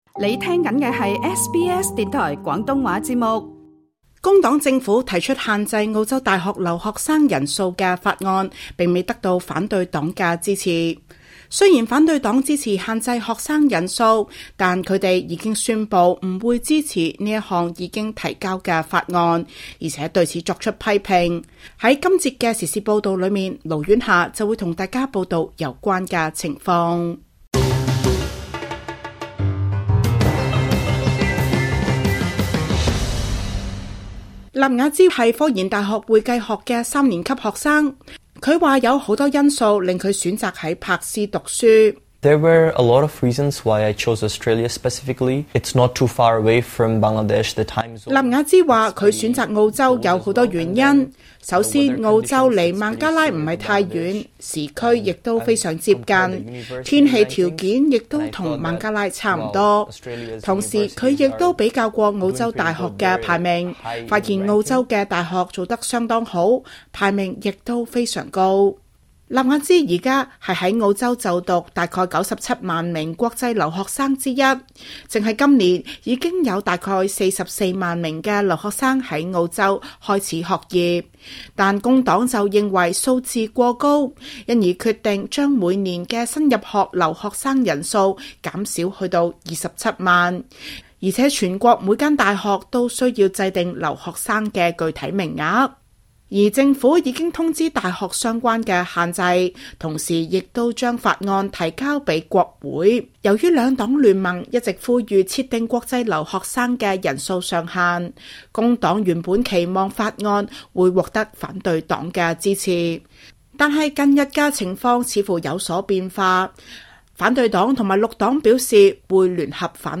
Source: SBS SBS廣東話節目 View Podcast Series Follow and Subscribe Apple Podcasts YouTube Spotify Download (4.94MB) Download the SBS Audio app Available on iOS and Android 工黨政府提出限制澳洲大學留學生人數的法案，並未得到反對黨的支持。